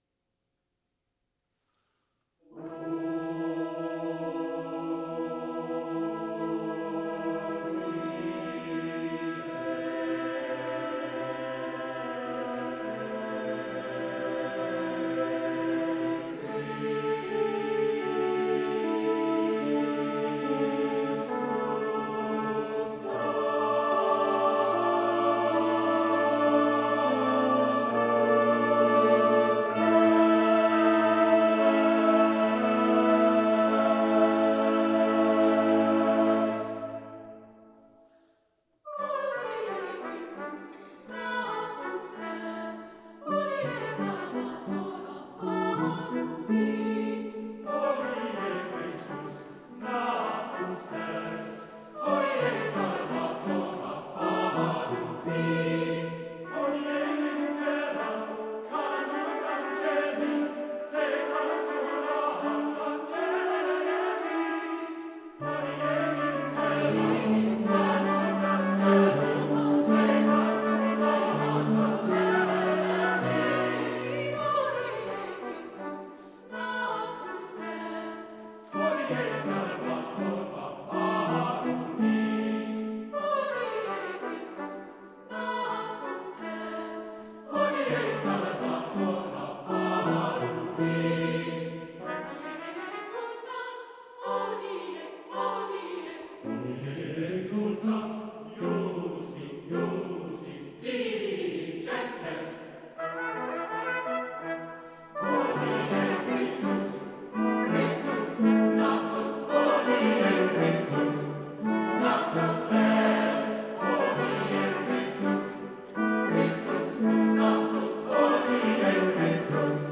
Voicing: SATB divisi and Organ